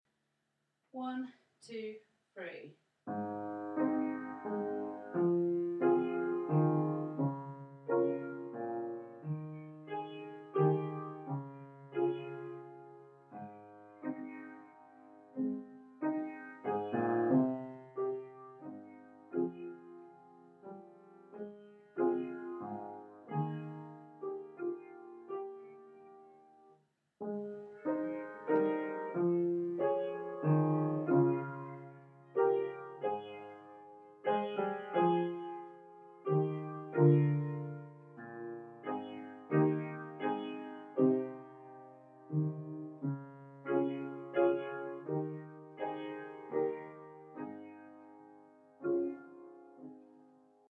Morning Has Broken Piano Part